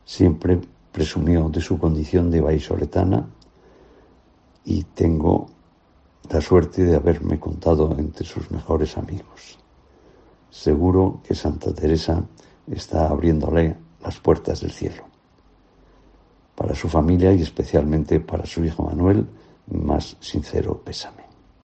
León de la Riva, exalcalde: "Siempre presumió de vallisoletana"
Javier León de la Riva, exalcalde de Valladolid, recuerda en COPE a Concha Velasco